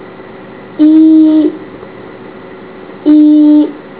下列介绍太魯閣族基本語言的發音，並列出太魯閣語字母表及發音表。